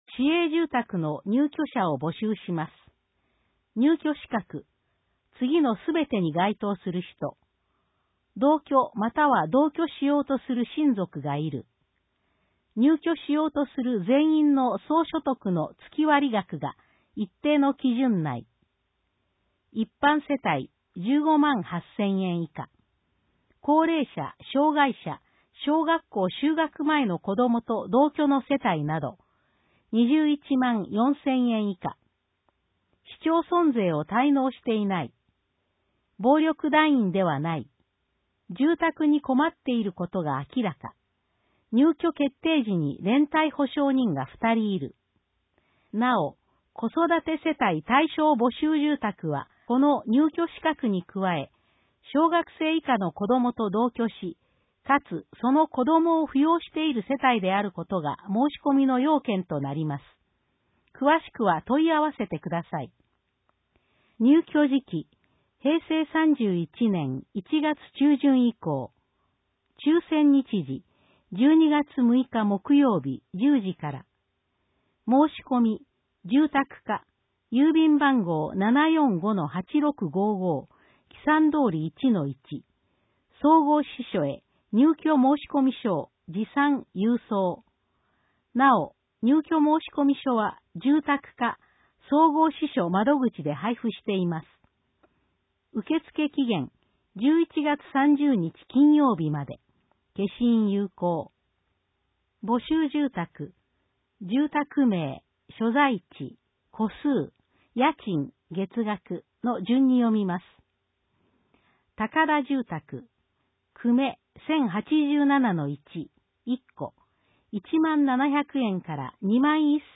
音訳広報
広報しゅうなんを、音読で収録し、mp3形式に変換して配信します。
この試みは、「音訳ボランティアグループともしび」が、視覚障害がある人のために録音している音読テープを、「周南視聴覚障害者図書館」の協力によりデジタル化しています。